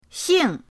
xing4.mp3